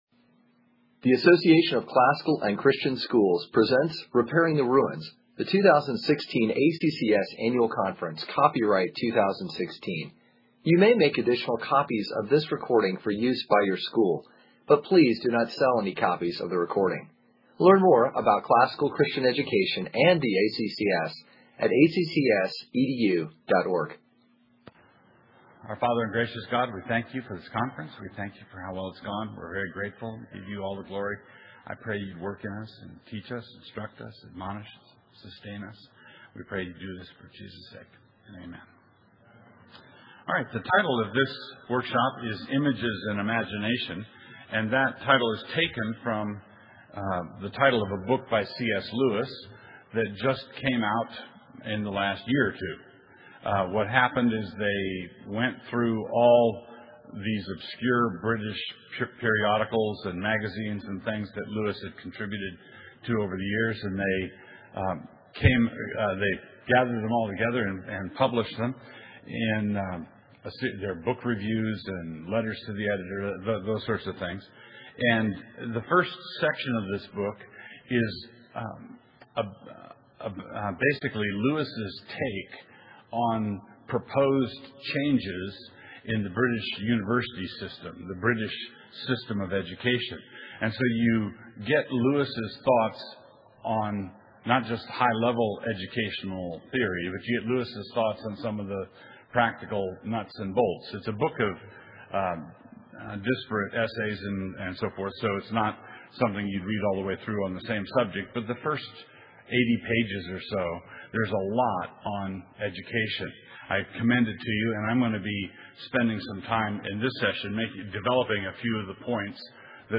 2016 Workshop Talk | 0:47:54 | All Grade Levels, General Classroom
Consider this session an extended review of several essays that Lewis wrote on the subject. Speaker Additional Materials The Association of Classical & Christian Schools presents Repairing the Ruins, the ACCS annual conference, copyright ACCS.